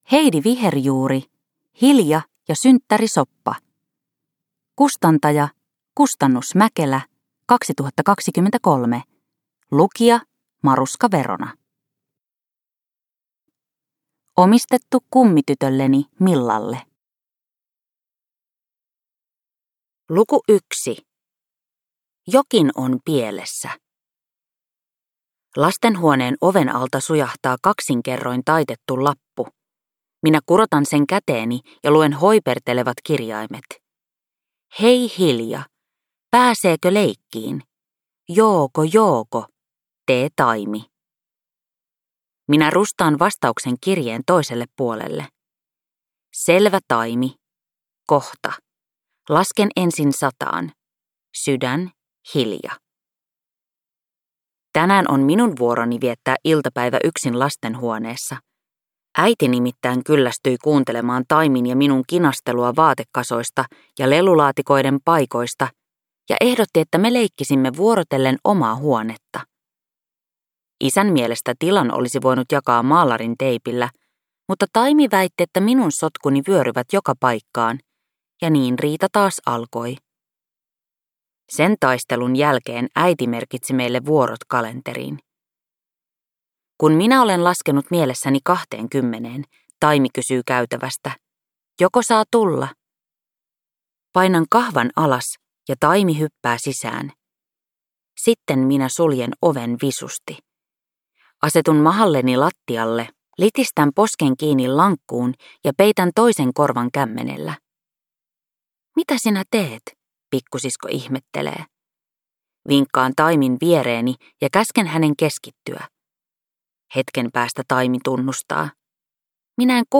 Kuunneltavissa myös äänikirjana useissa eri äänikirjapalveluissa